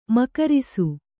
♪ makarisu